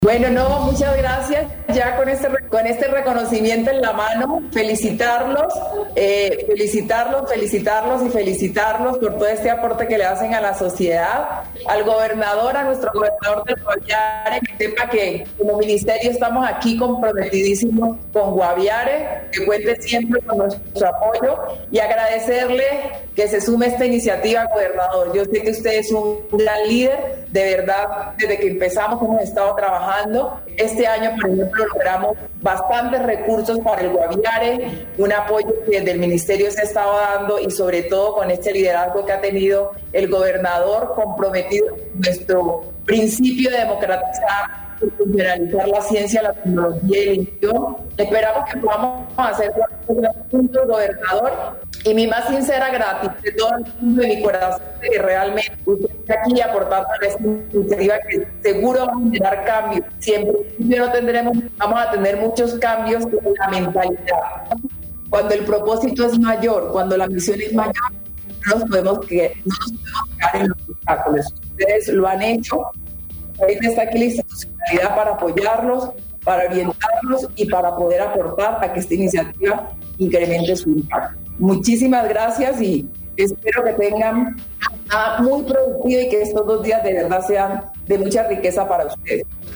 Escuche a Mabel Torres, ministra de Ciencia, Tecnología e Innovación